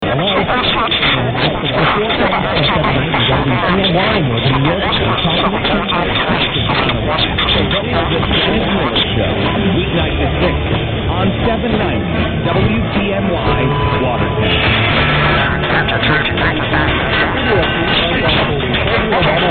790 WTNY, Watertown, NY 0700 with a fair peak